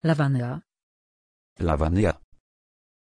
Aussprache von Lavanya
pronunciation-lavanya-pl.mp3